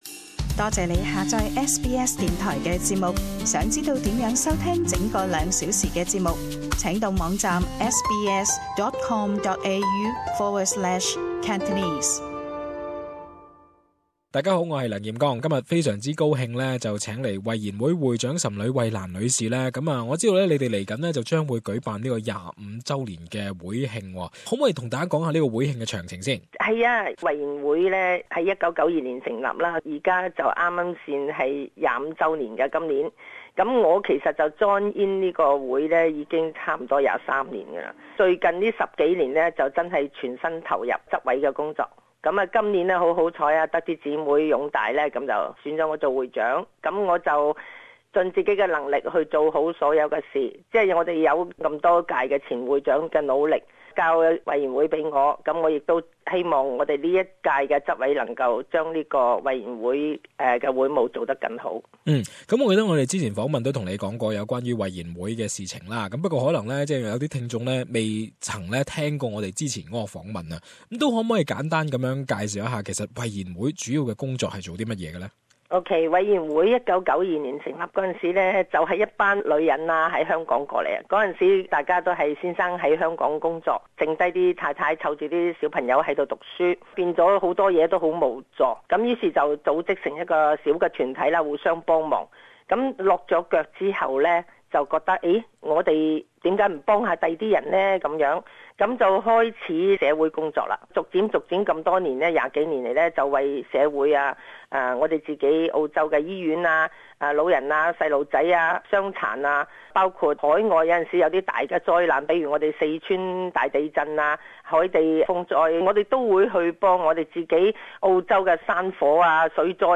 Community interview: Way In Network 25th annuniversary